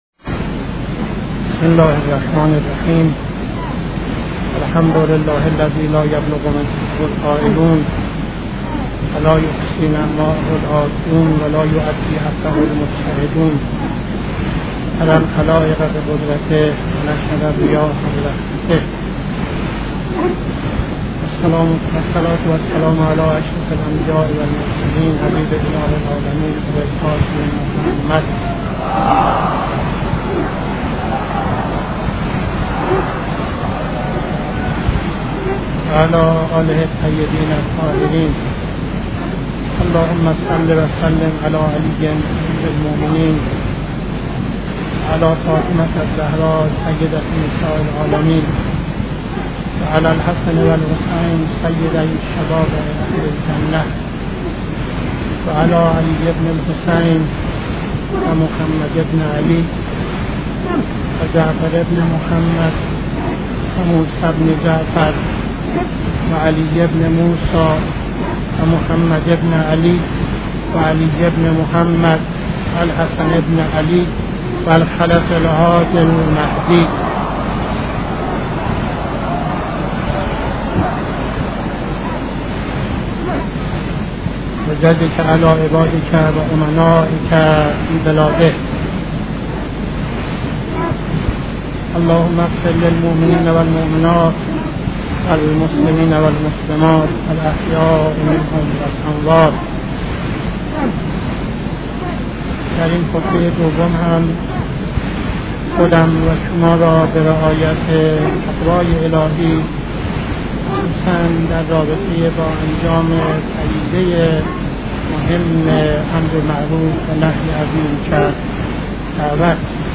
خطبه دوم نماز جمعه 06-09-71